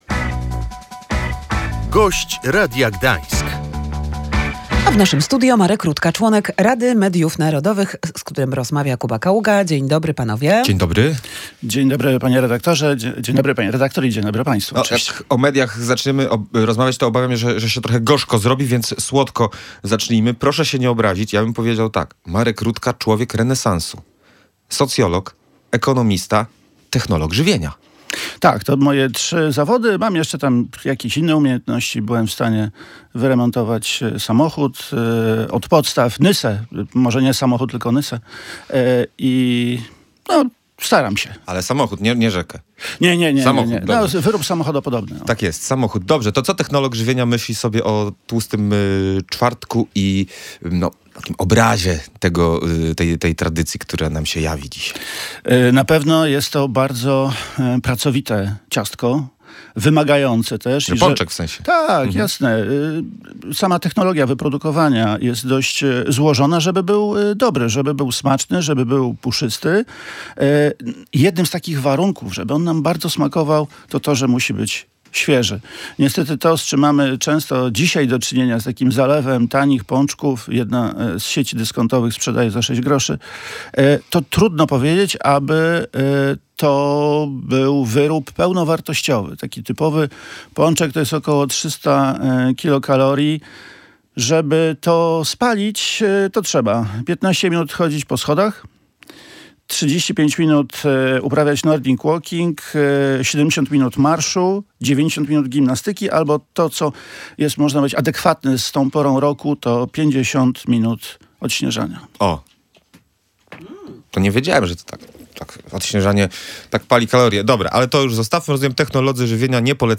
W kształcie, w jakim została przygotowana, prawdopodobnie nie zostanie podpisana przez prezydenta – mówił w Radiu Gdańsk Marek Rutka, członek Rady Mediów Narodowych.